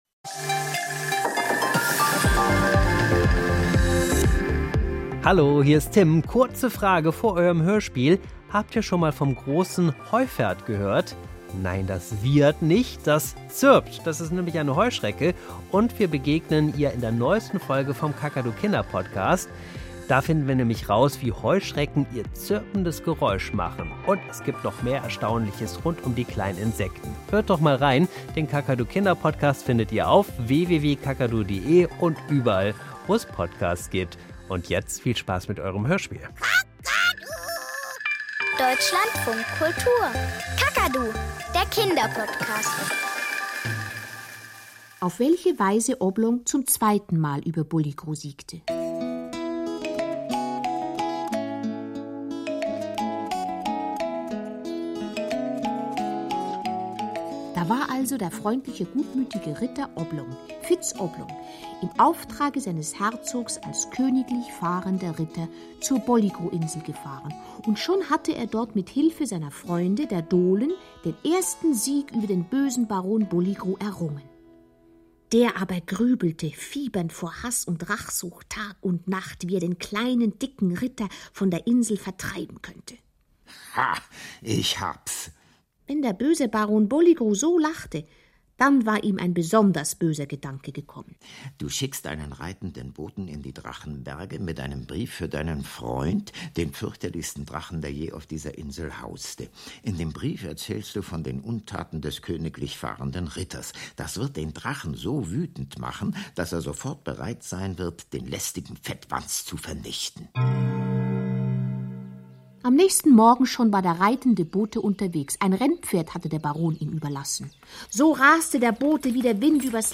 Kinderhörspiel - Der kleine dicke Ritter (2/6)